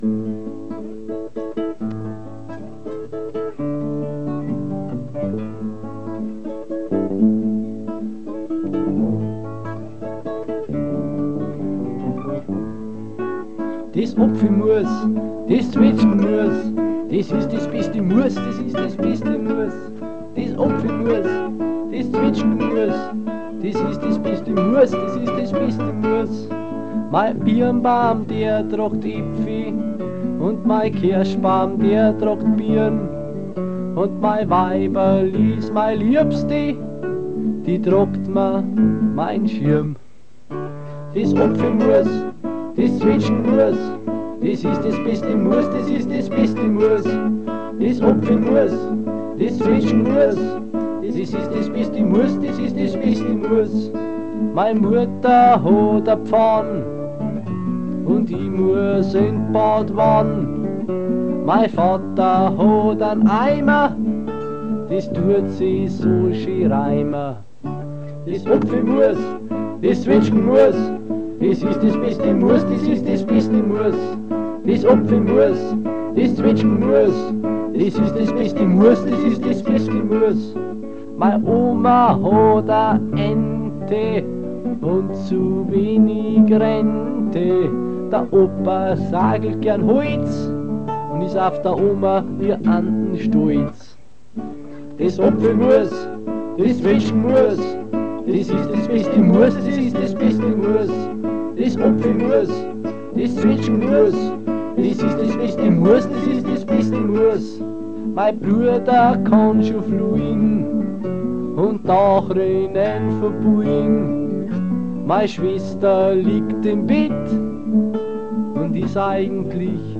Vozwickte Mundartsongs